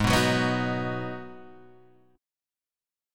G#m7 chord